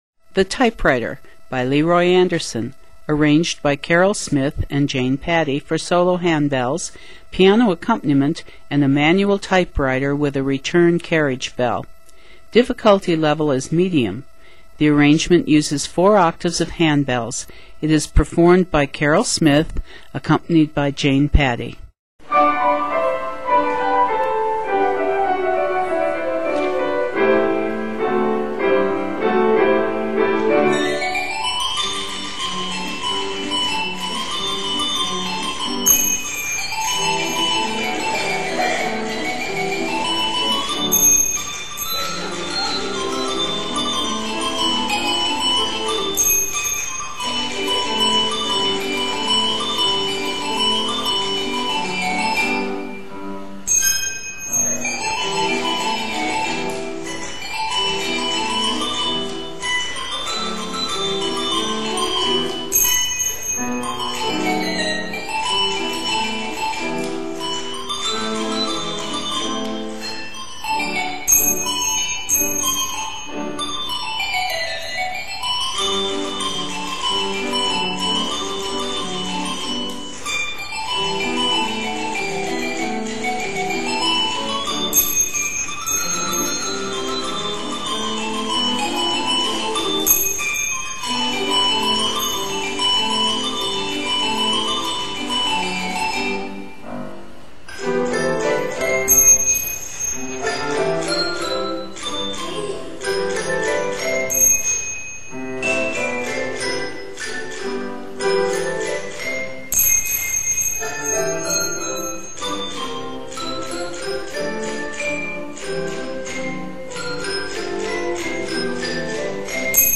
In the key of G Major, measures total 107.